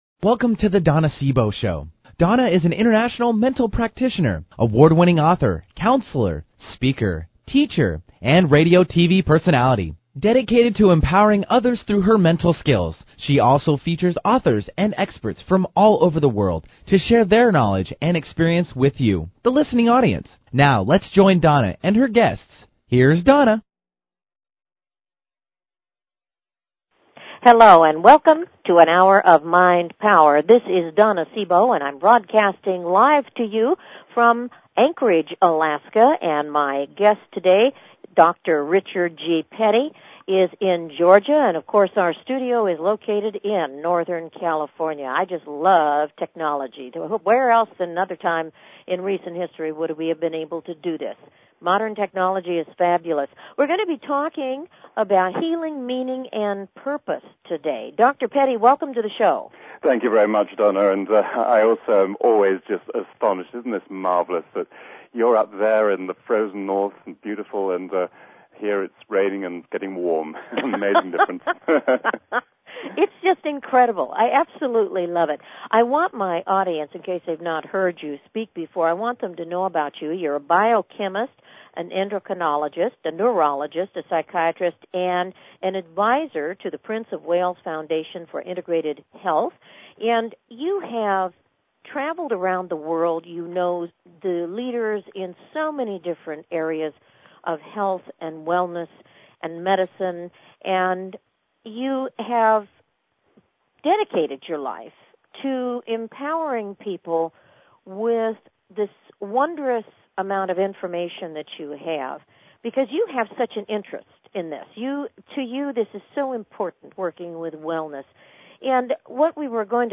Her interviews embody a golden voice that shines with passion, purpose, sincerity and humor.